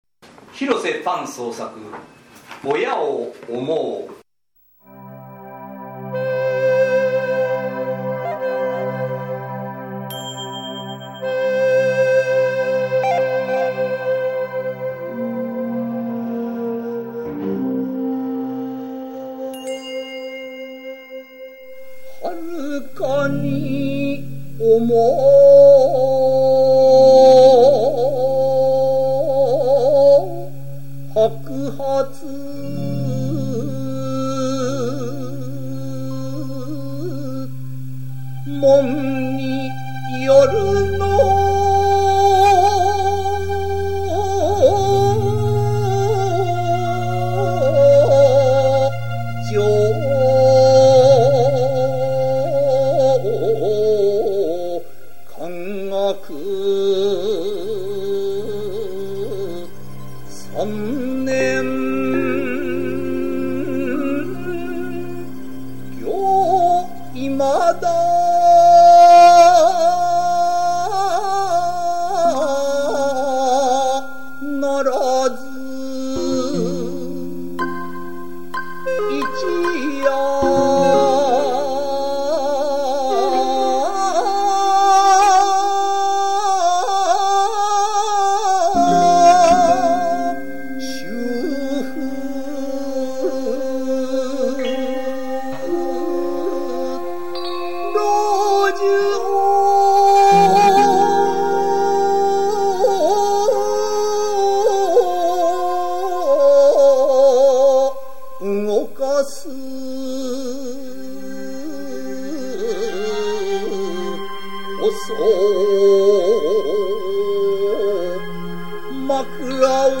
漢詩紹介
平起こり七言絶句の形であって、下平声八庚（こう）韻の情、成、驚の字が使われている。